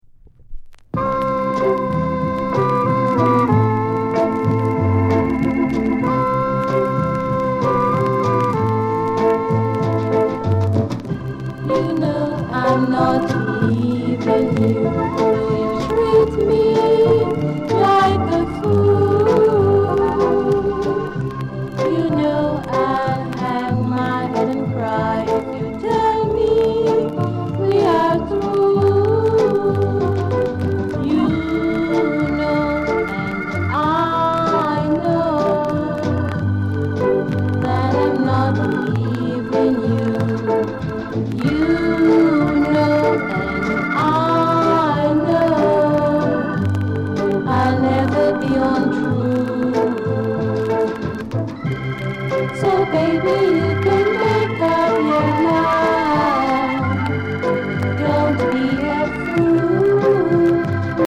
RARE SKA